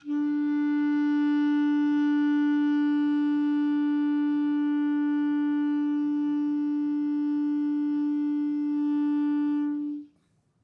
Clarinet
DCClar_susLong_D3_v2_rr1_sum.wav